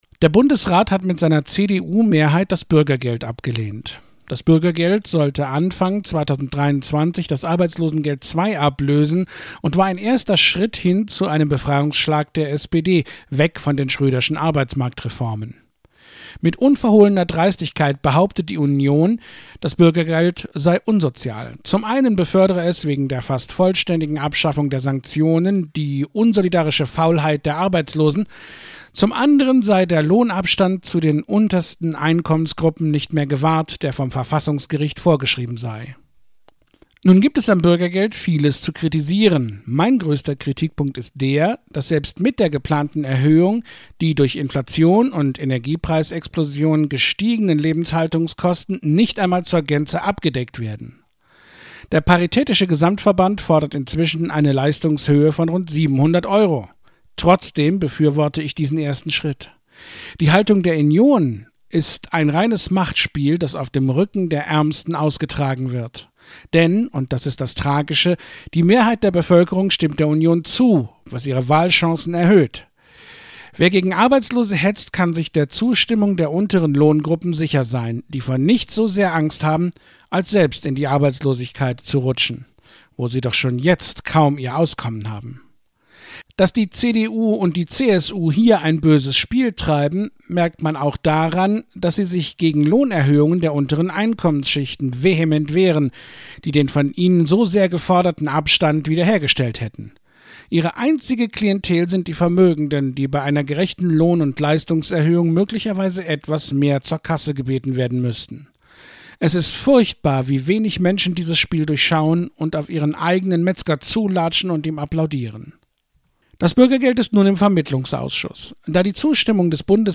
Er liest seine Kommentare der Sprachausgabe in seinem Ohr nach, das Lesen mit der ist langsamer. Ach ja: Es geht ums .